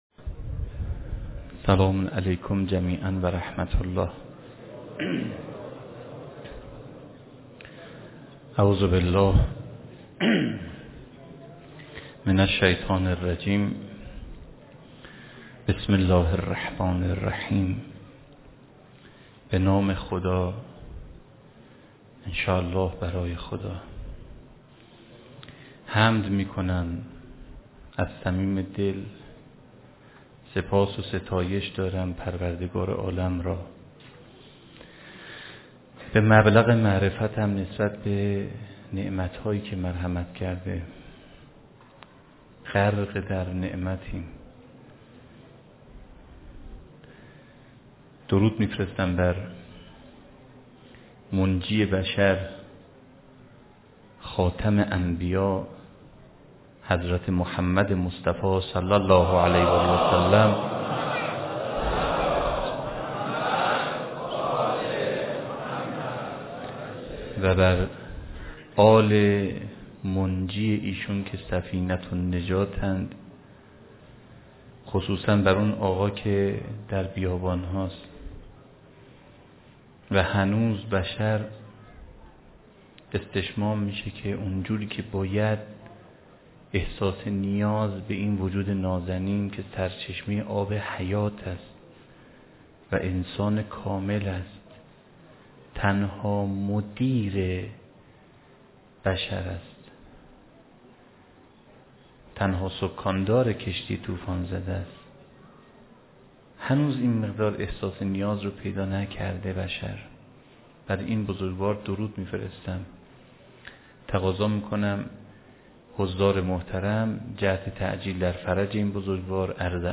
سخنرانی
فاطمیه 93 برازجان